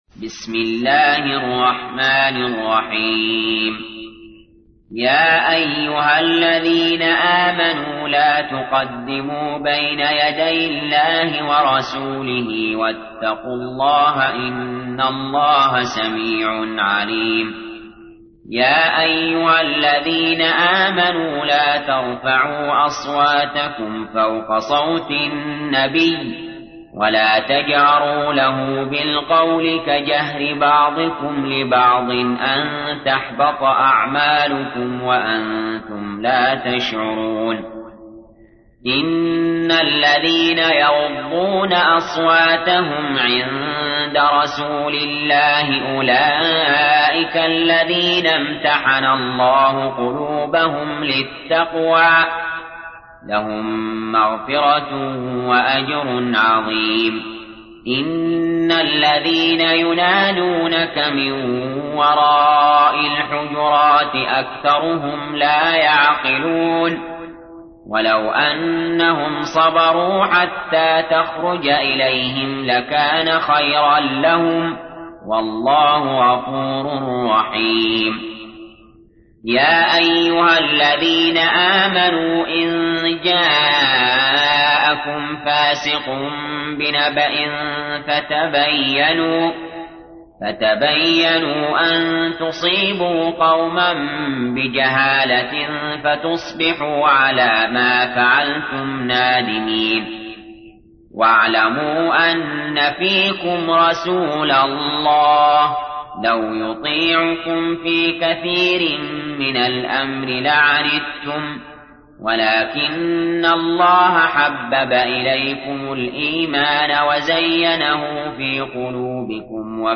تحميل : 49. سورة الحجرات / القارئ علي جابر / القرآن الكريم / موقع يا حسين